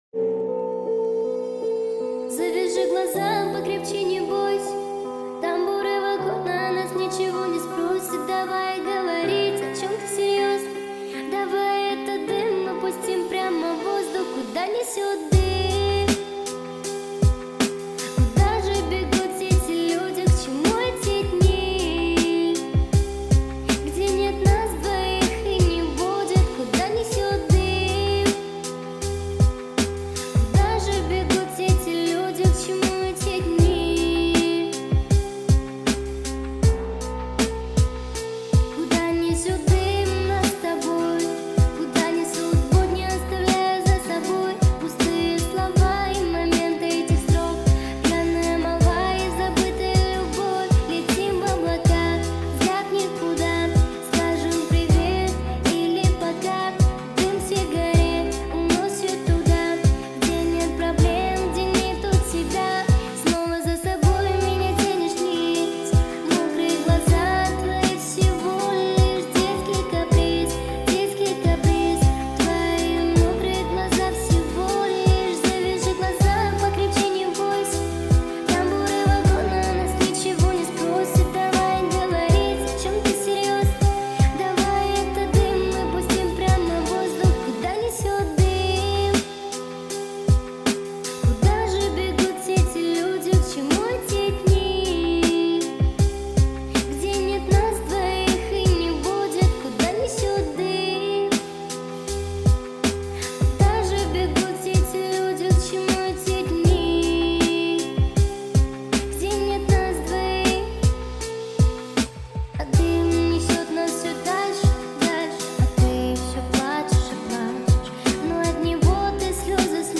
поп-рок